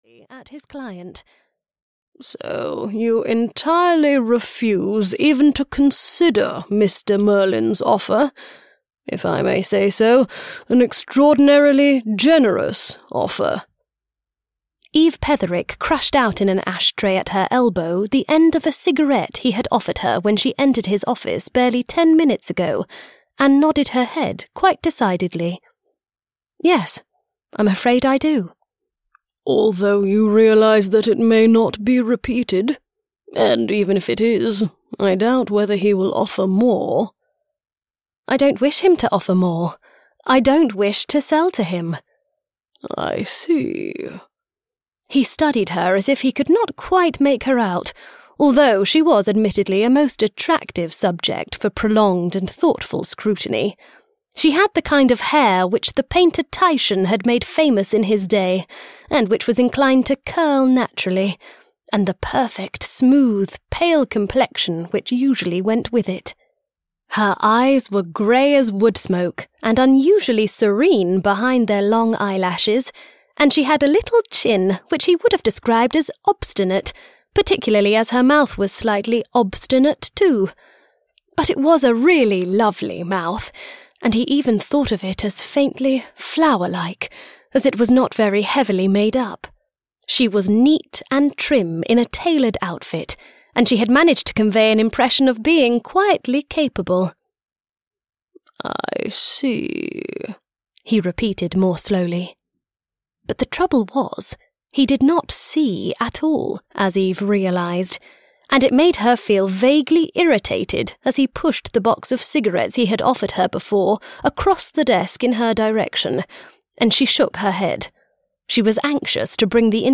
Hotel Stardust (EN) audiokniha
Ukázka z knihy